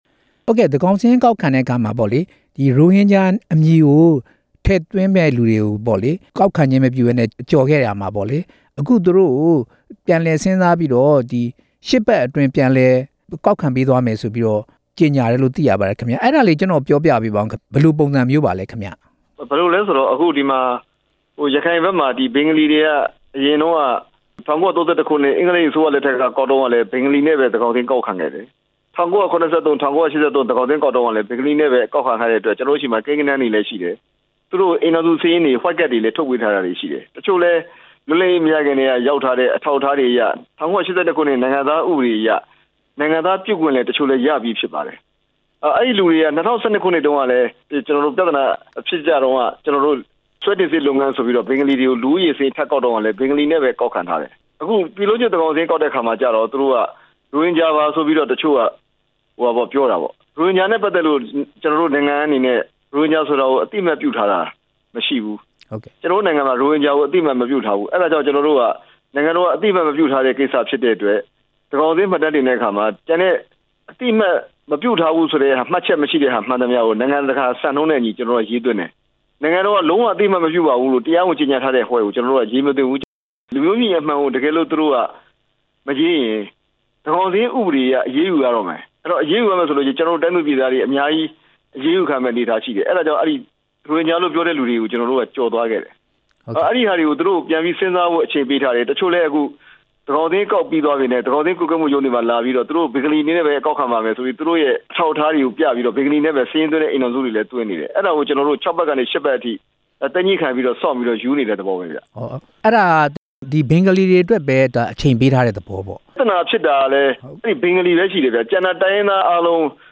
ညွန်ကြားရေးမှူးချုပ် ဦးမြင့်ကြိုင်နဲ့ မေးမြန်းချက်